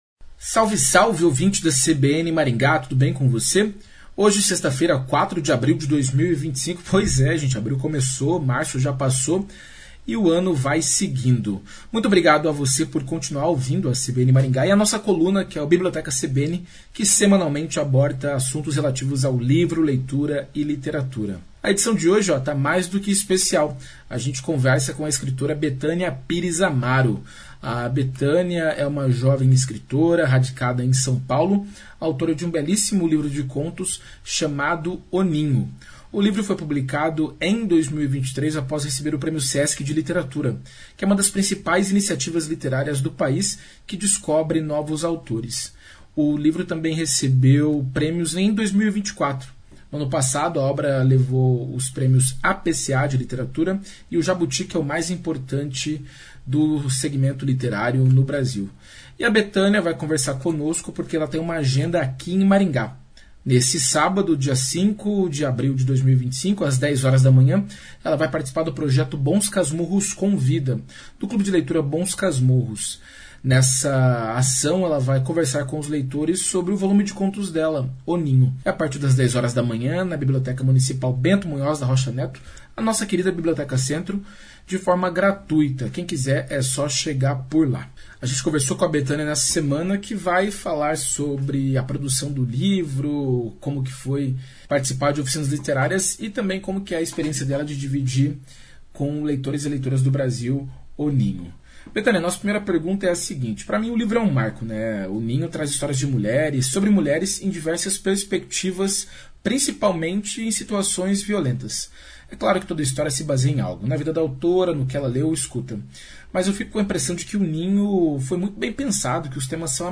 Em conversa com a coluna, ela falou sobre a produção do livro a recepção dos leitores.